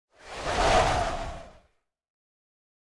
Minecraft Version Minecraft Version snapshot Latest Release | Latest Snapshot snapshot / assets / minecraft / sounds / mob / breeze / idle3.ogg Compare With Compare With Latest Release | Latest Snapshot